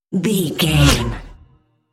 Pass by sci fi fast
Sound Effects
Fast
futuristic
pass by
vehicle